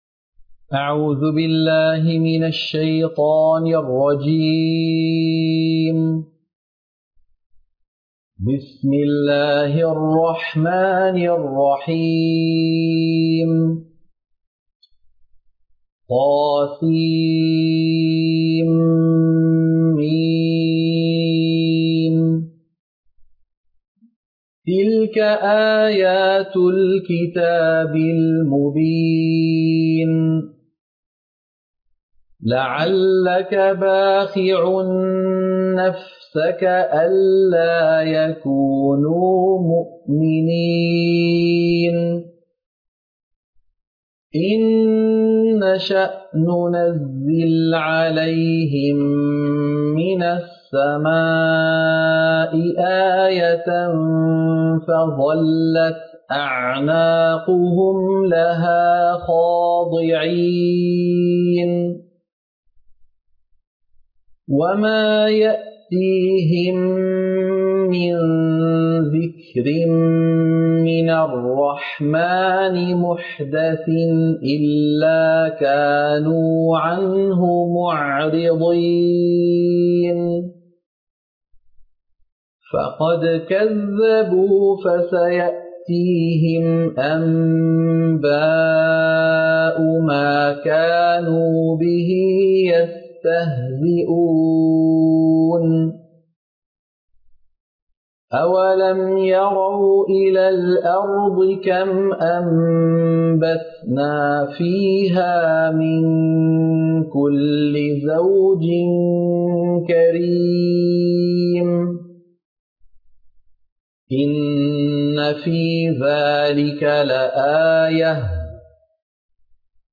سورة الشعراء - القراءة المنهجية